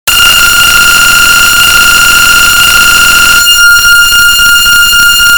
School Bell Ringing Earrape
school-bell-ringing-earrape.mp3